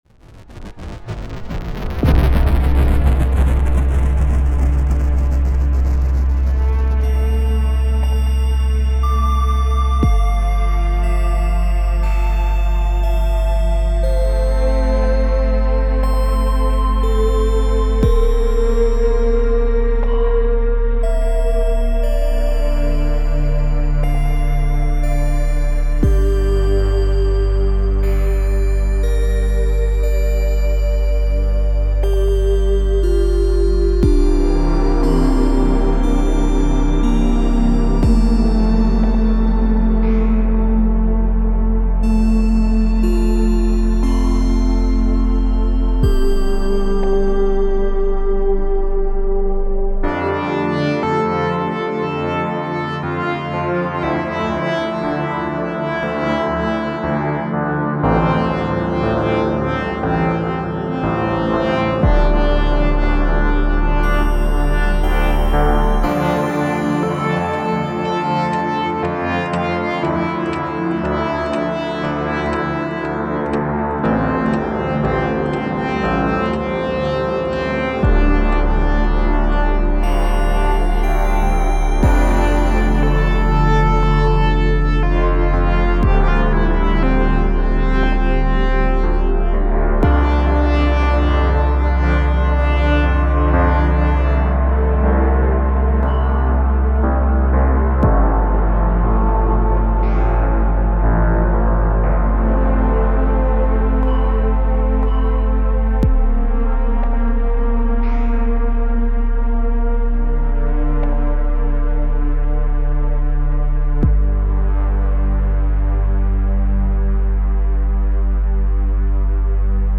music
electronic